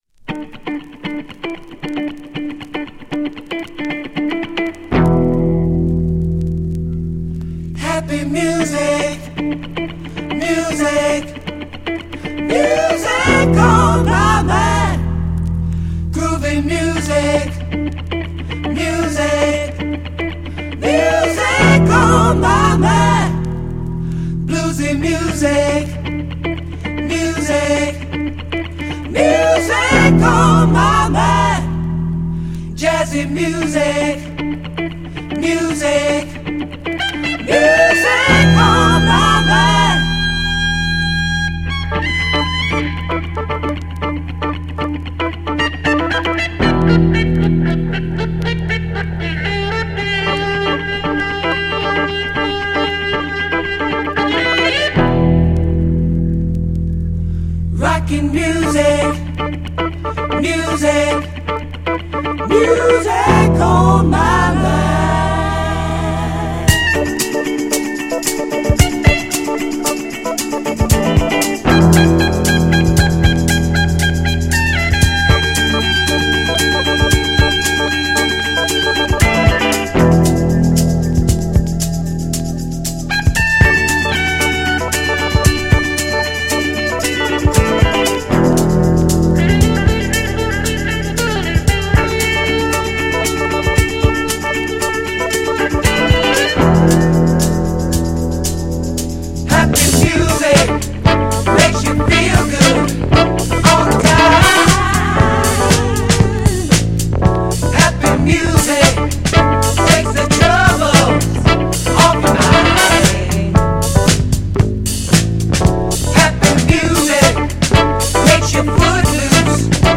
GENRE Dance Classic
BPM 111〜115BPM
FUNKグループ
ソウル
ハートウォーム # フルート